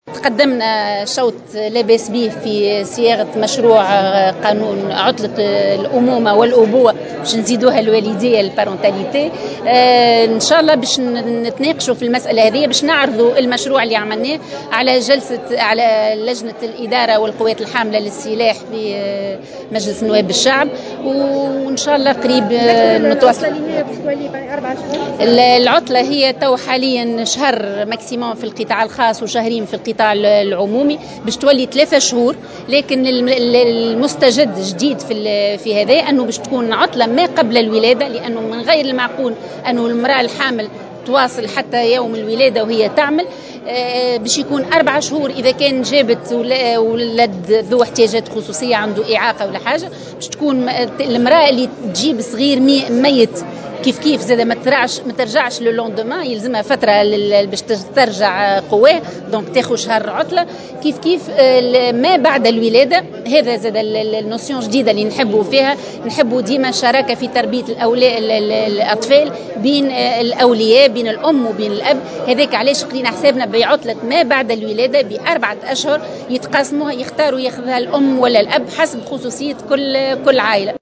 وأكدّت الوزيرة في تصريح لمراسلة الجوهرة أف أم بالجهة، التقدم بشكل كبير في صياغة مشروع قانون يهدف إلى تمتيع أحد الوالديْن بعطلة أمُومة أو أبوة بـ 3 أشهر، مضيفة أن سيتم عرض مشروع القانون خلال الفترة القادمة على لجنة الإدارة بالبرلمان.